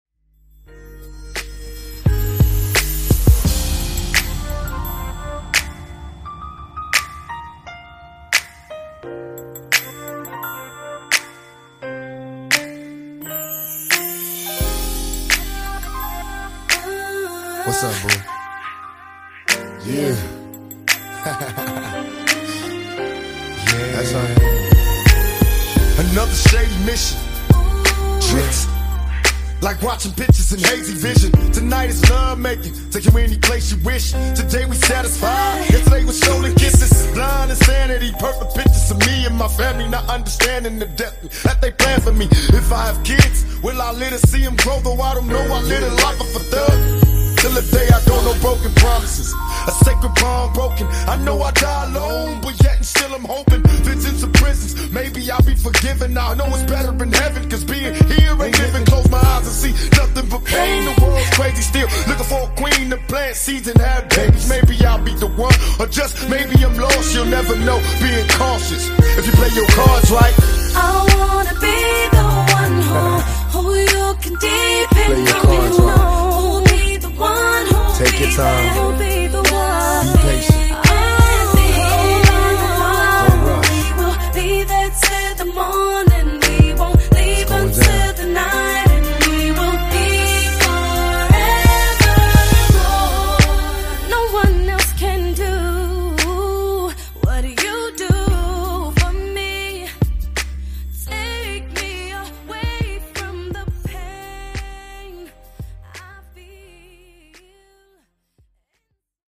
Genre: RE-DRUM
Clean BPM: 86 Time